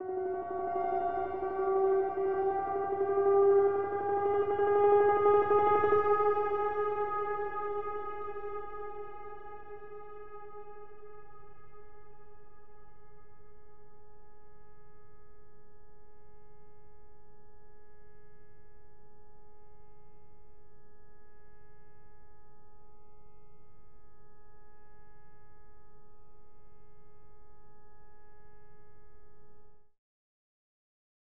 Piano Nudes Mve 1 Artificial Reverb.wav